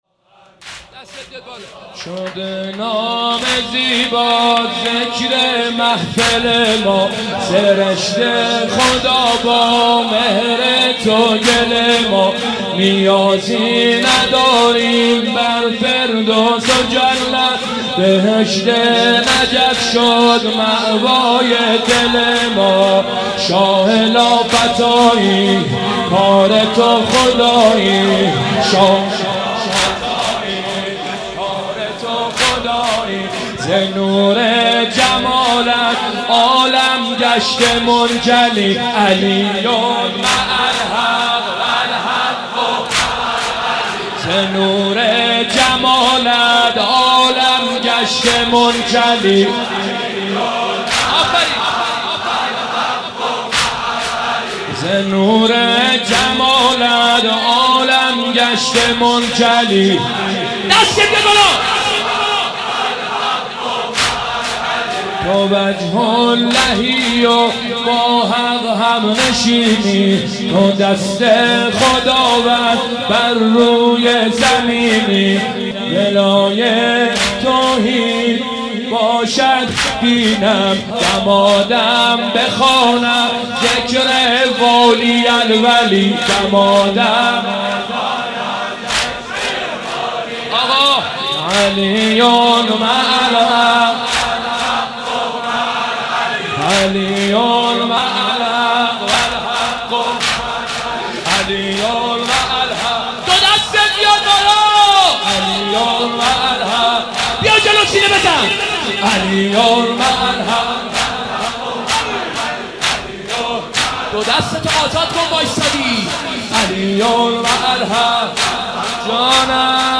مناسبت : شب نوزدهم رمضان - شب قدر اول